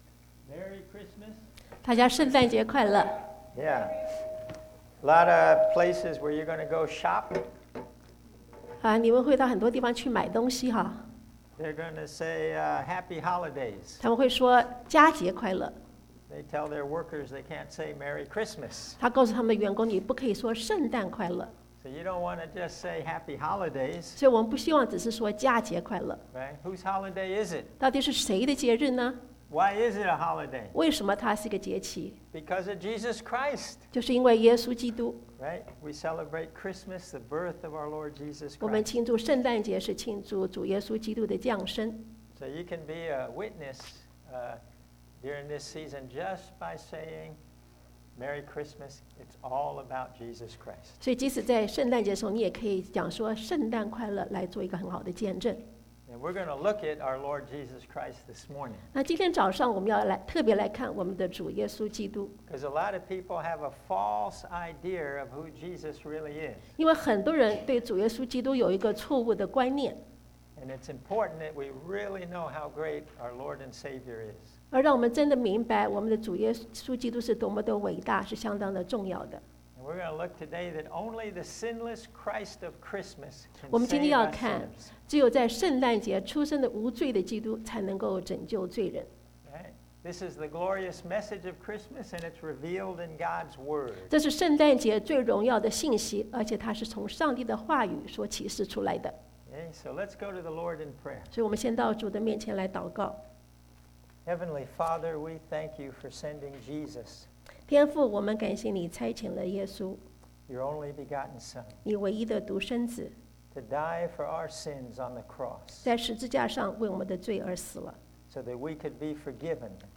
Bilingual Sermon